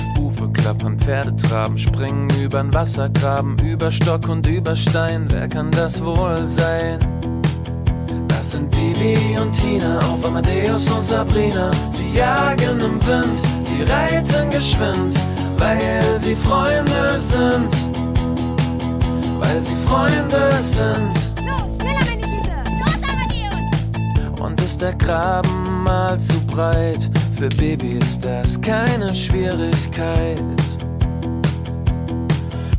Kategorien Filmmusik